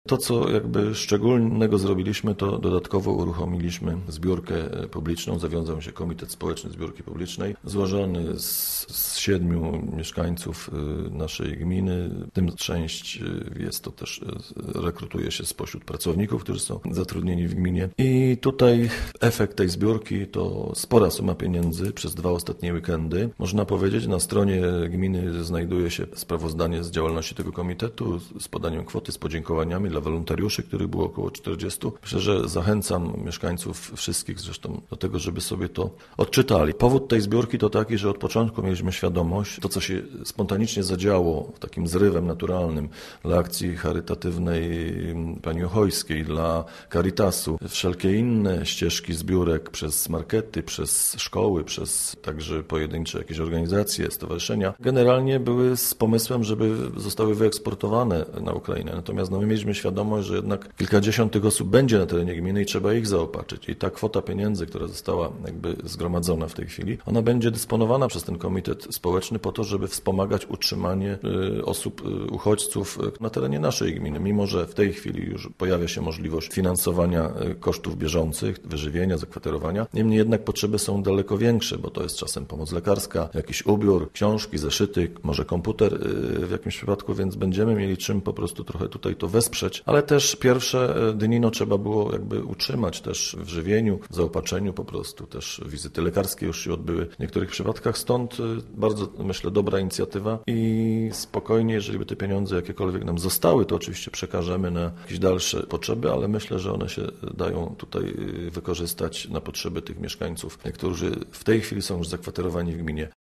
Co najmniej 112 uchodźców przebywa już w gminie Biała. Zawiązał się tam społeczny komitet na rzecz pomocy Ukraińcom, który zebrał ponad 26 tys. zł podczas dwóch zbiórek publicznych. Mówi wójt Aleksander Owczarek: Zawiązał się komitet złożony z 7 mieszkańców, część rekrutuje się spośród pracowników zatrudnionych w gminie.